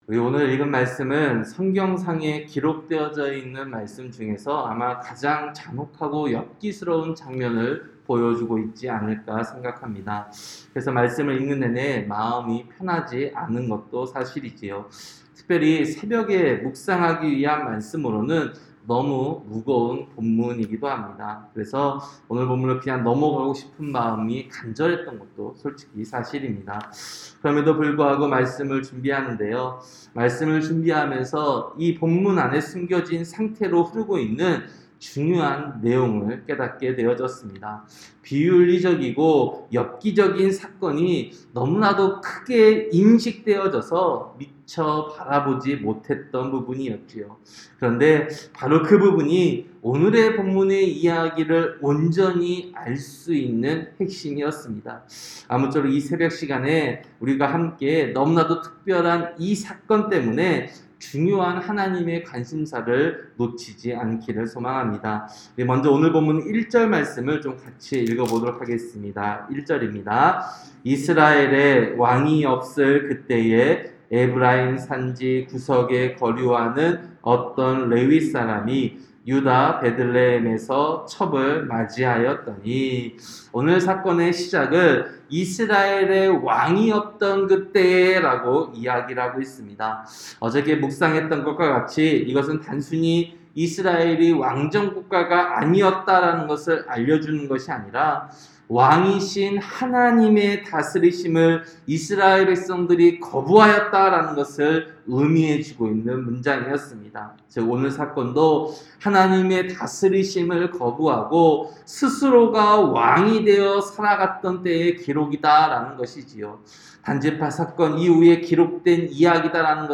새벽설교-사사기 19장